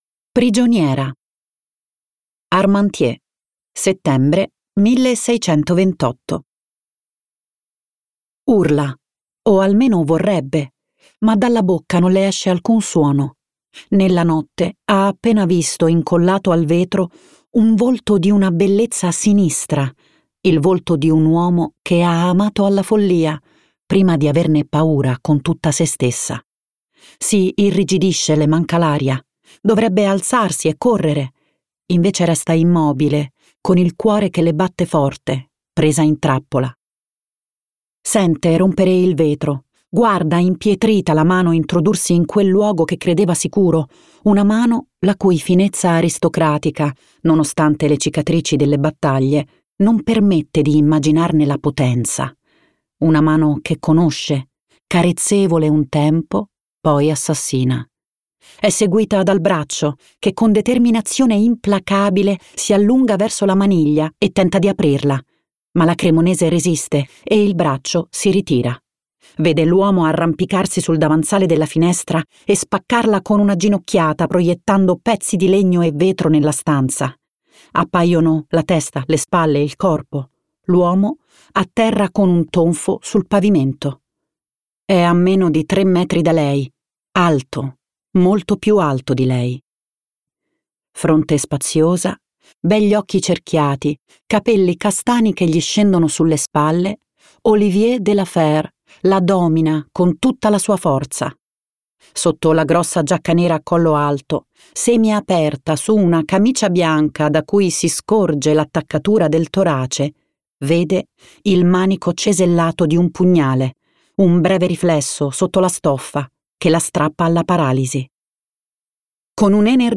Versione audiolibro integrale